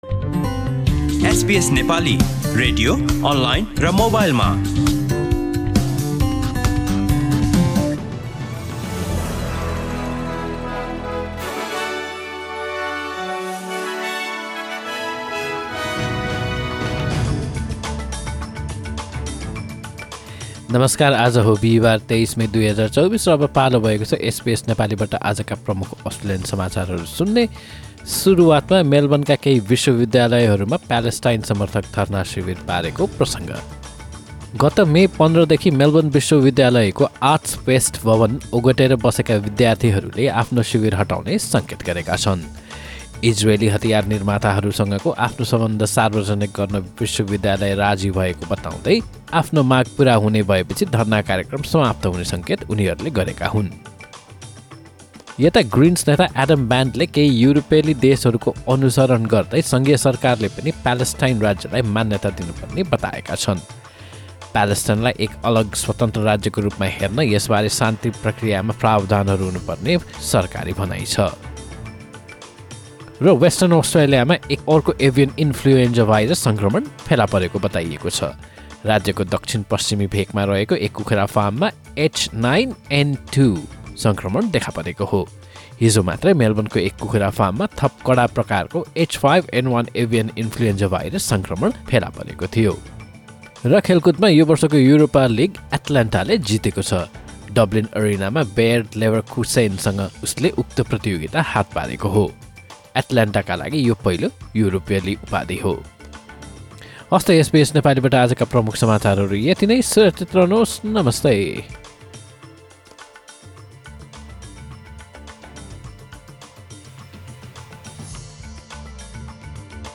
SBS Nepali Australian News Headlines: Thursday, 23 May 2024